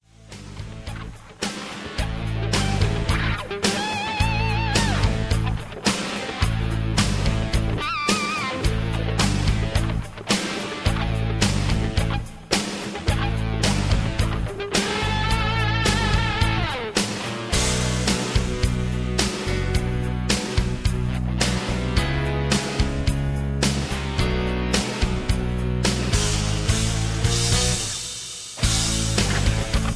Tags: karaoke collection , backing tracks , sound tracks